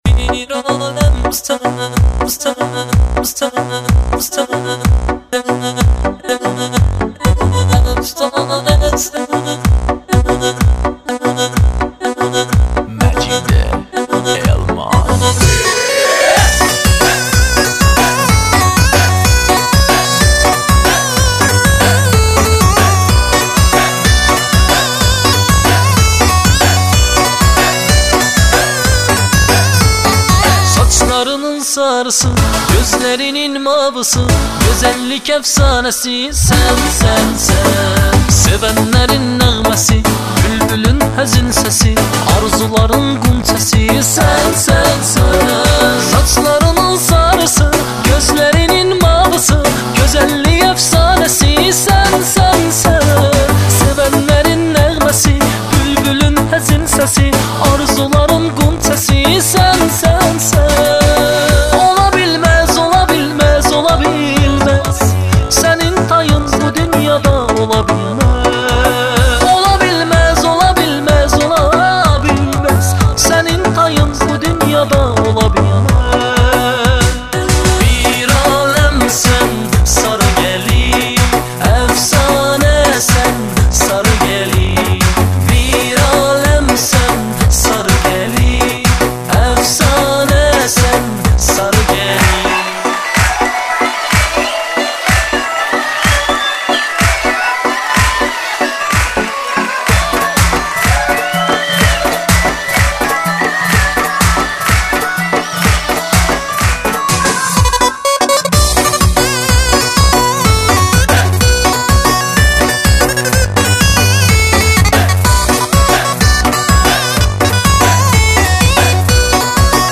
آهنگ شاد عروسی آذری